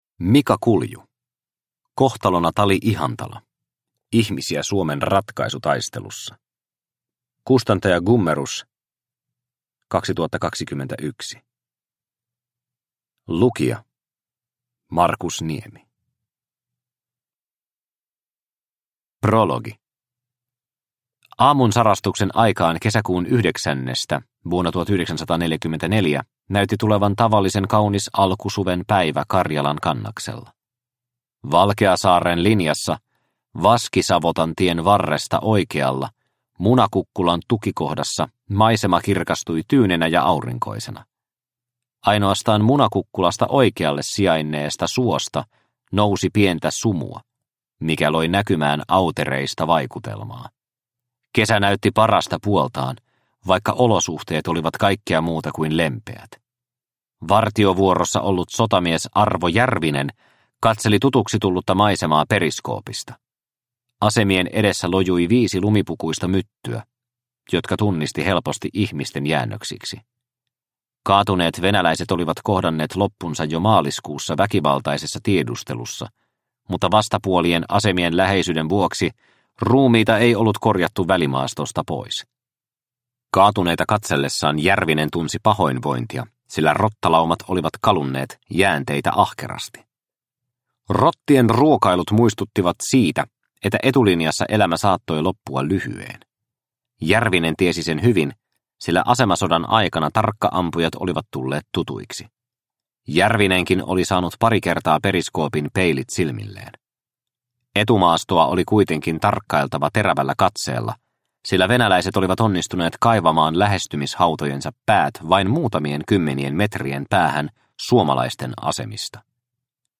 Kohtalona Tali-Ihantala – Ljudbok – Laddas ner